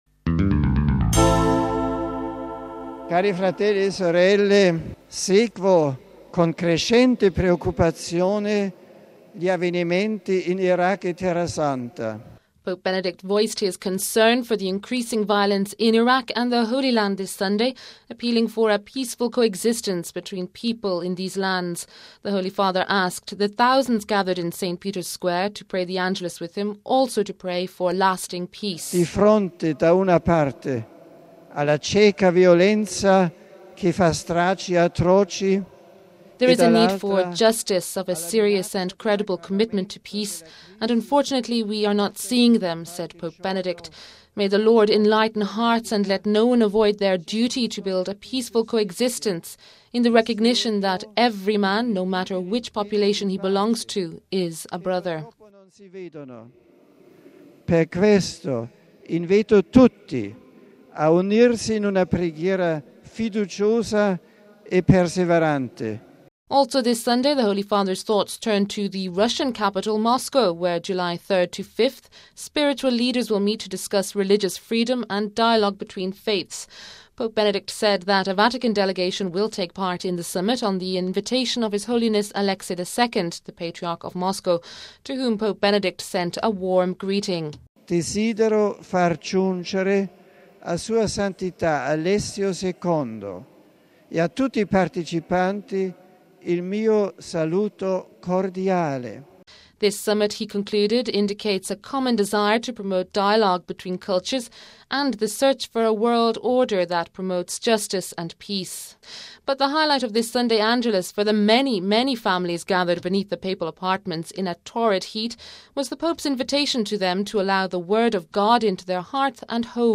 report